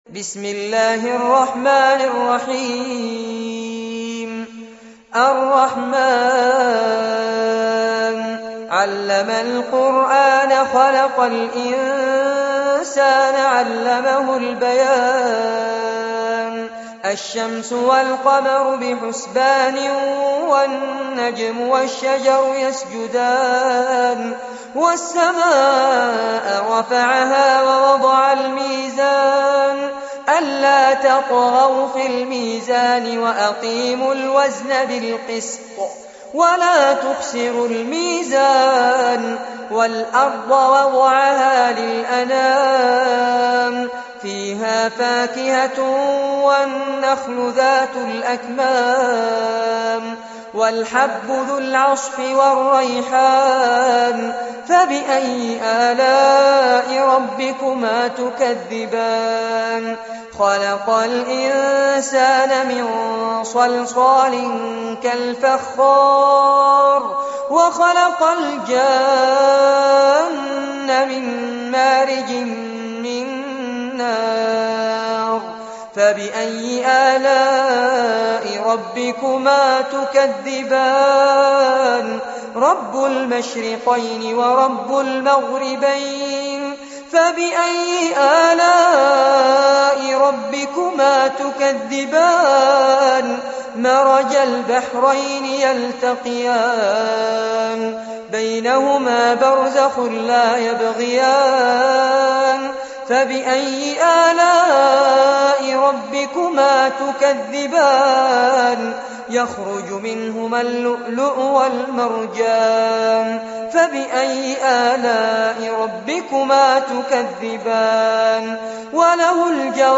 عنوان المادة سورة الرحمن- المصحف المرتل كاملاً لفضيلة الشيخ فارس عباد جودة عالية